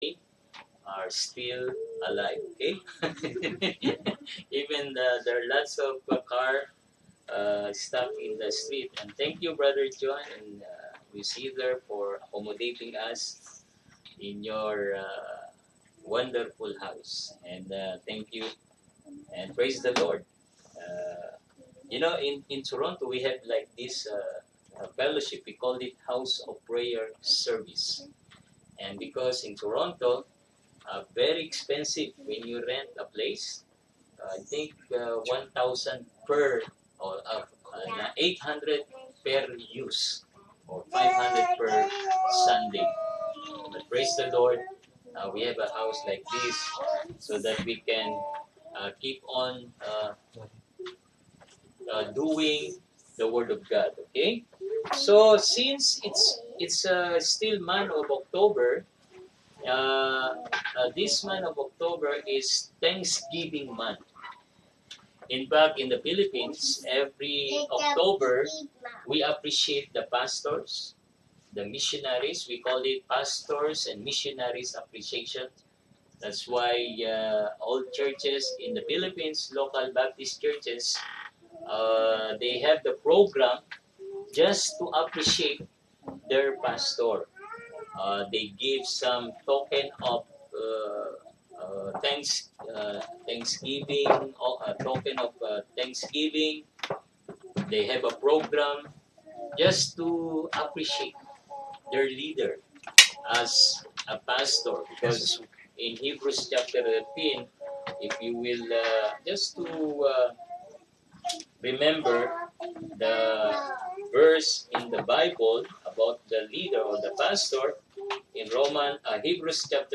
Sunday PM Home Service for Oct. 23